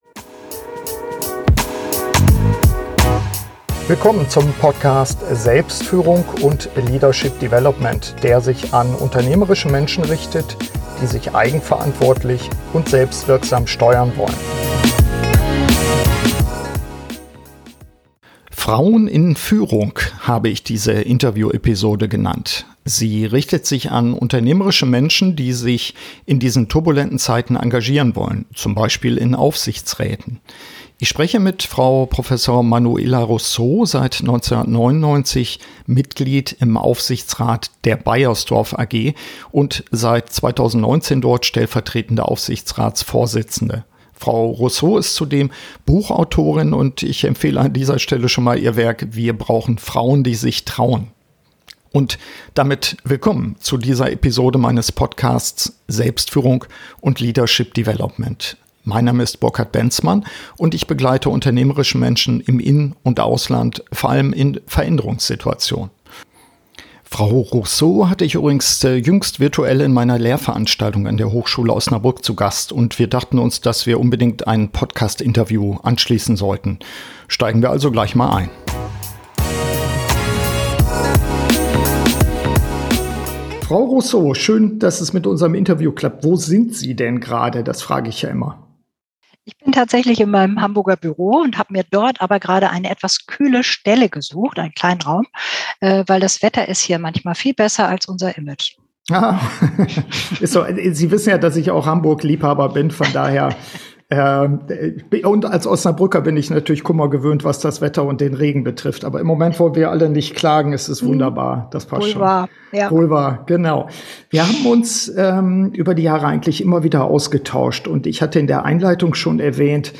Beschreibung vor 3 Jahren „Frauen in Führung“ habe ich diese Interview-Episode genannt. Sie richtet sich an unternehmerische Menschen, die sich in diesen turbulenten Zeiten engagieren wollen, z.B. in Aufsichtsräten.